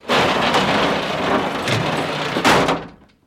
Door, Metallic, Slide Up, With Servo Pull